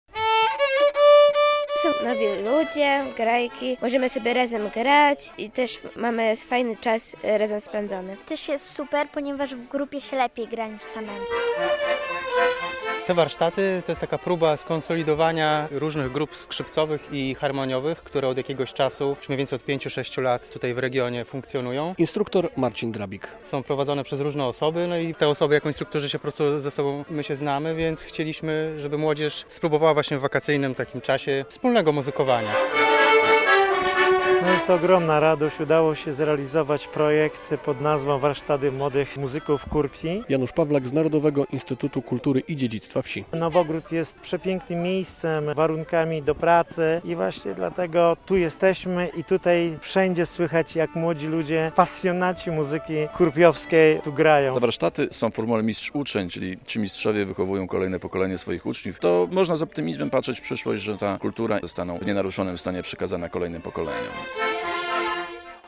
Muzyczne "Półkolonie Kurpiowskie" w Nowogrodzie - relacja